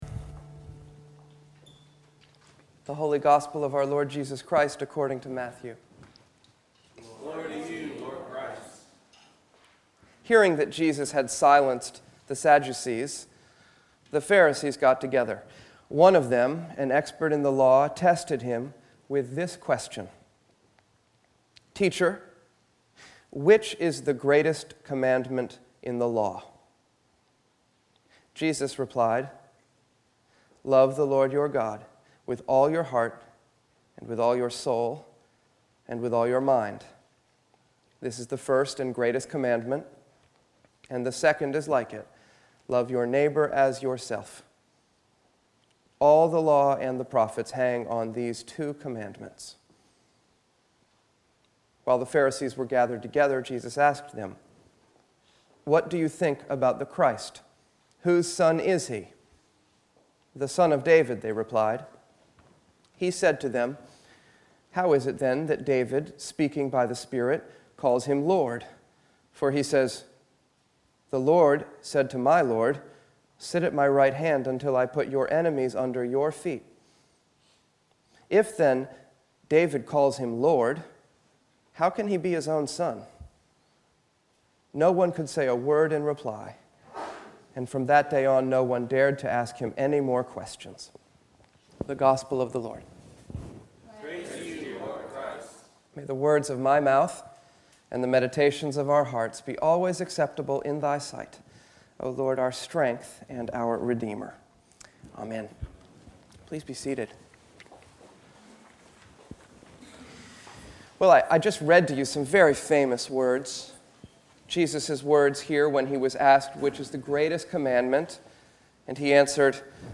Venue: Church Of The Holy Cross